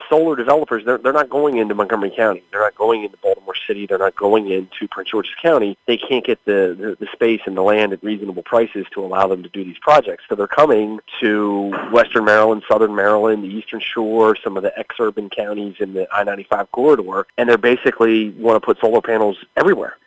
House Minority Leader Jason Buckel says the bill unfairly targets rural areas for solar placement…